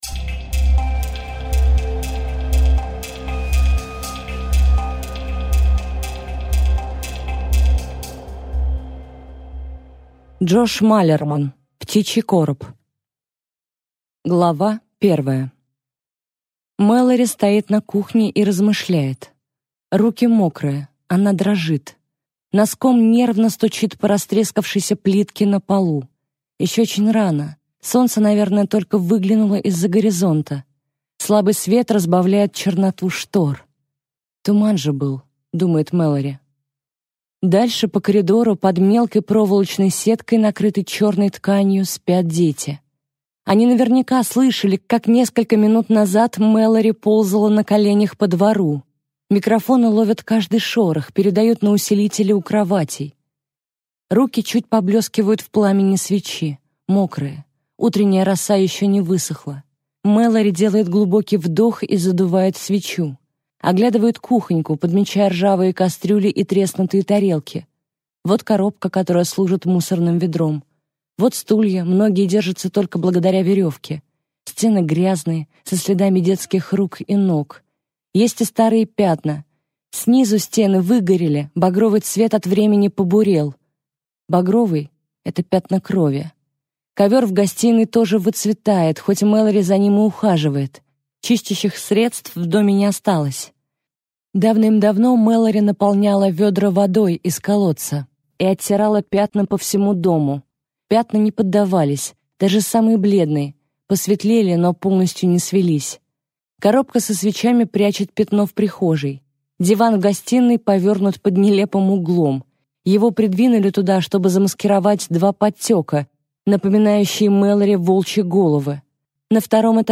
Аудиокнига Птичий короб | Библиотека аудиокниг